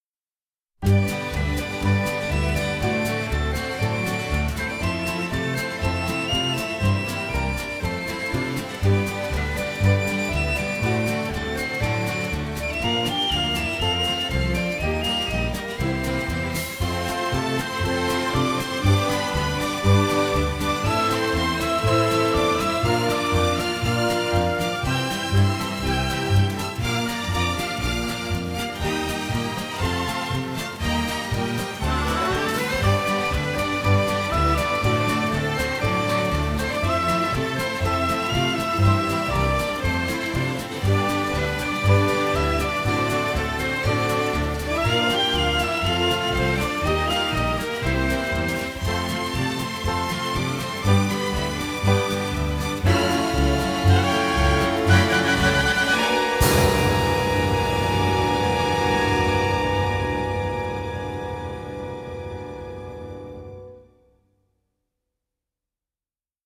composed for full orchestra.